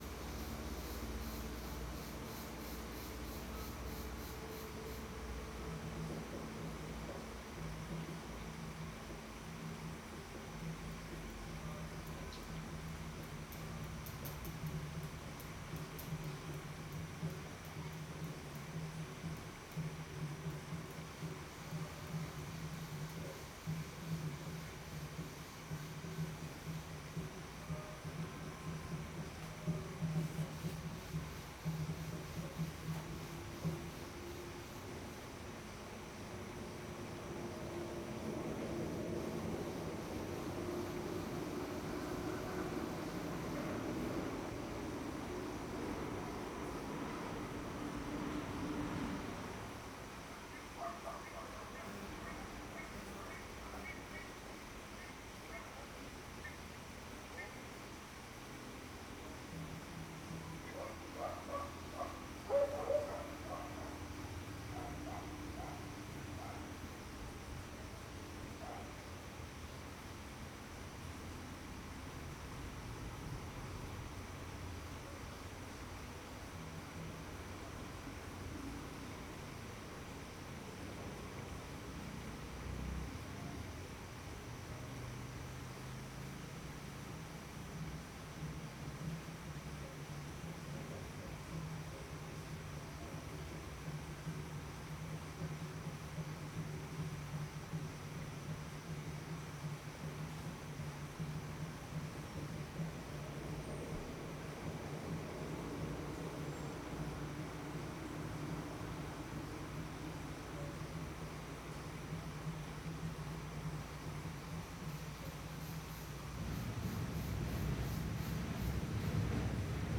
CSC-05-004-LE - Ambiencia riacho fim de tarde cigarras musica longe aviao passando e cachorro latindo.wav